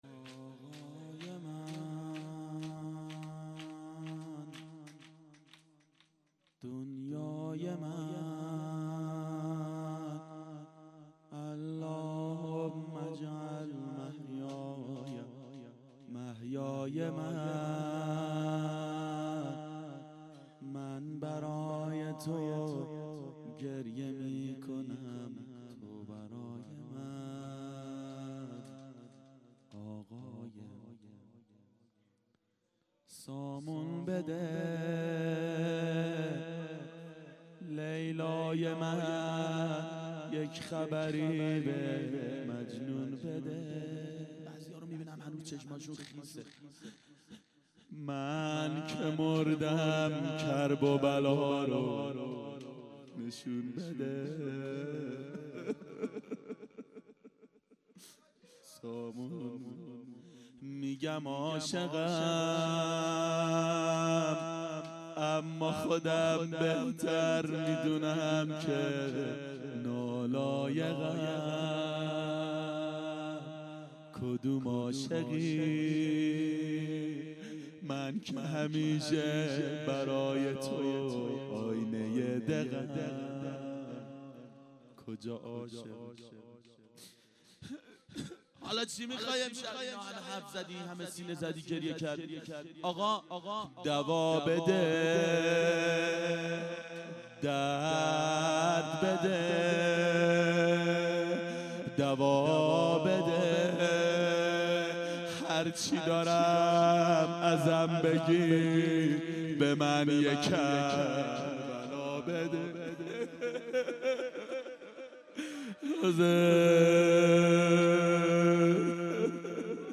دهه اول صفر سال 1392 هیئت شیفتگان حضرت رقیه سلام الله علیها